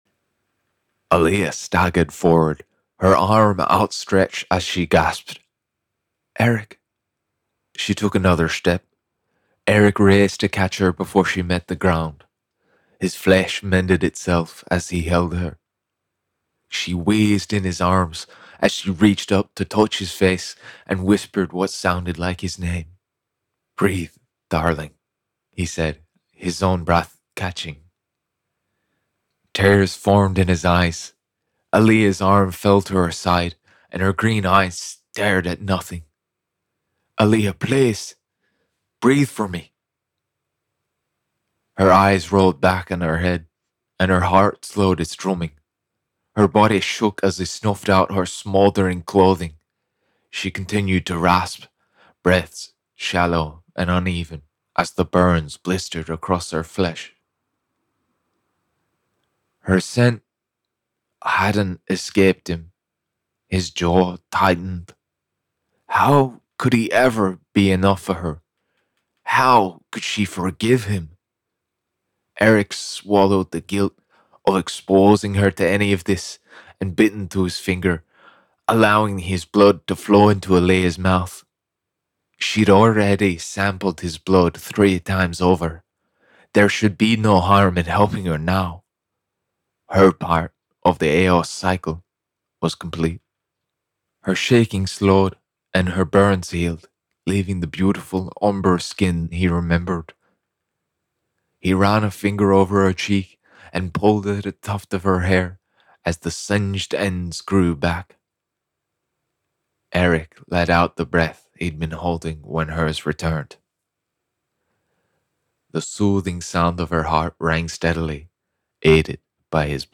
Fantasy Novel - Eos Rising [IRISH, CHARACTER]
Young Adult